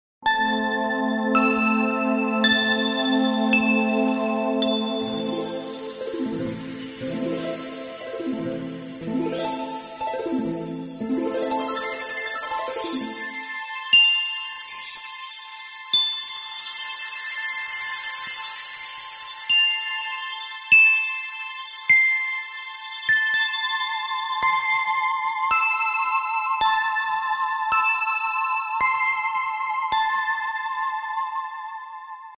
• Quality: High